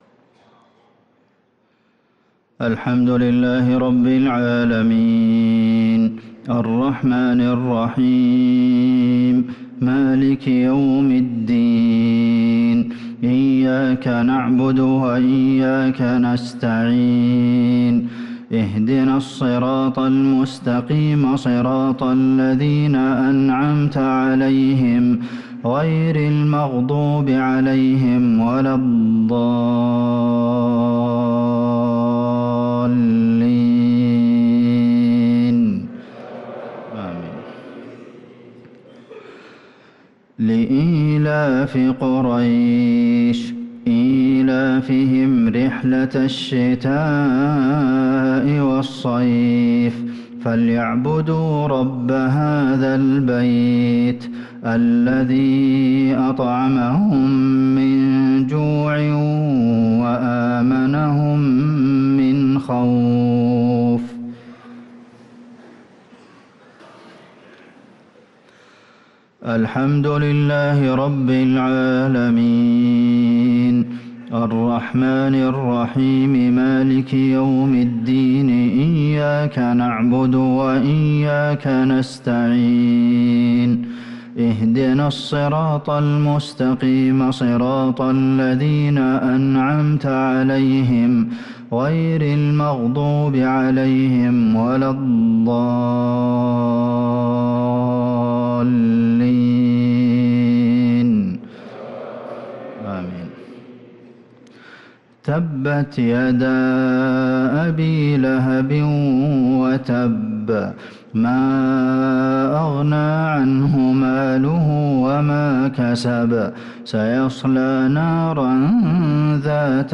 صلاة المغرب للقارئ عبدالمحسن القاسم 30 ذو الحجة 1444 هـ
تِلَاوَات الْحَرَمَيْن .